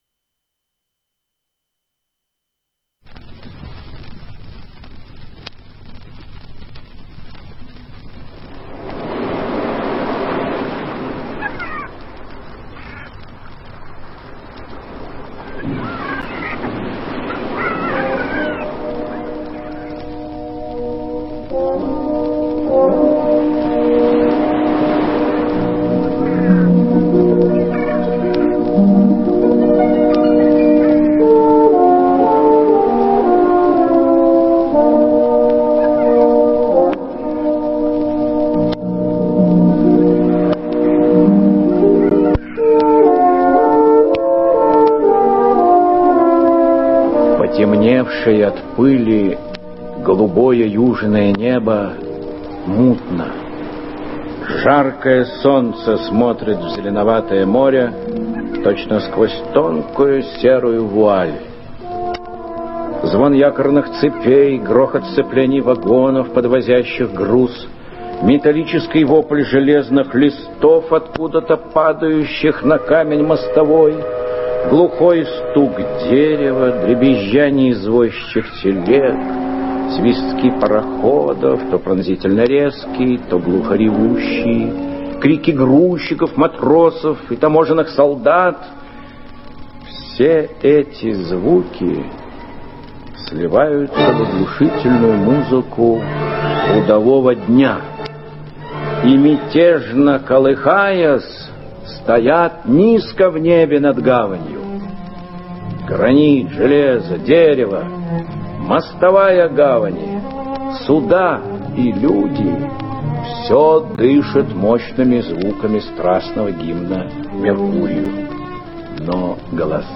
Челкаш - аудио рассказ Горького - слушать онлайн